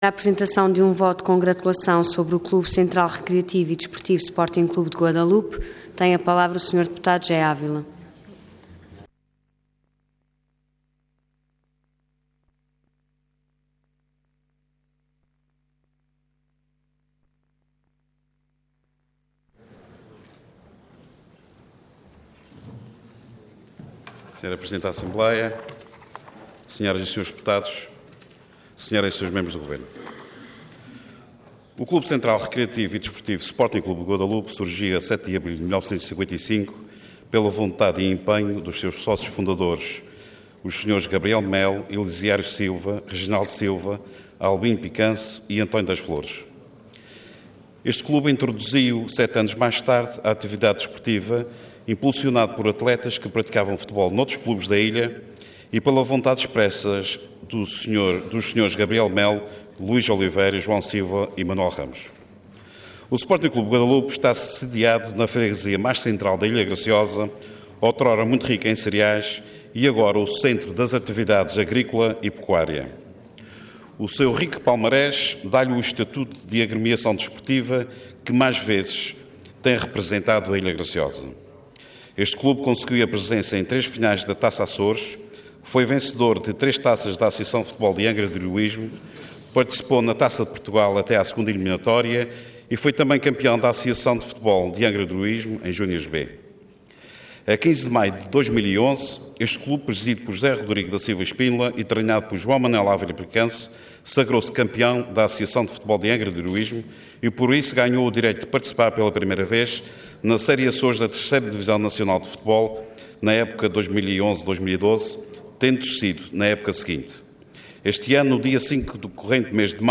Intervenção Voto de Congratulação Orador José Ávila Cargo Deputado Entidade PS